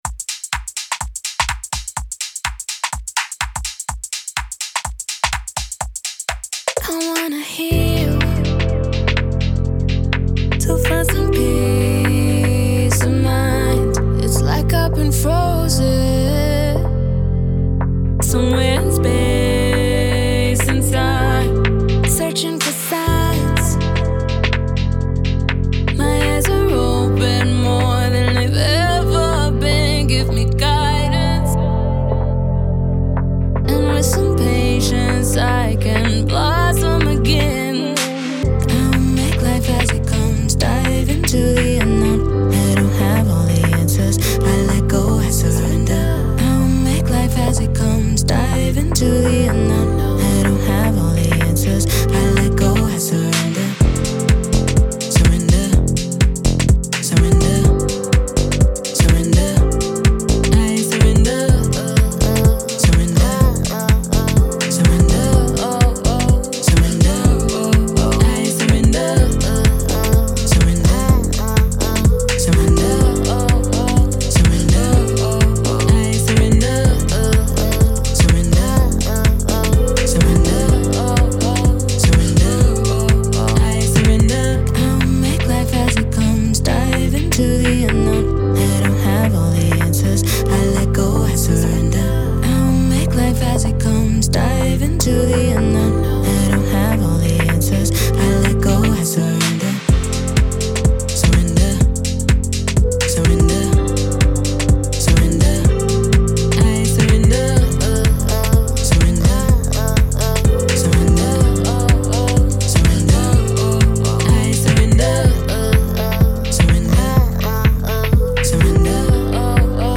House, Pop
F maj